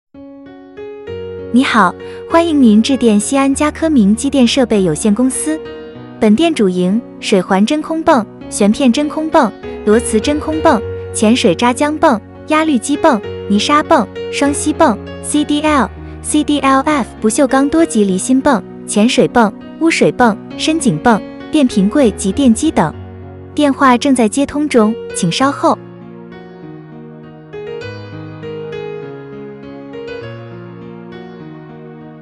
投稿用户 音频彩铃 阅读 923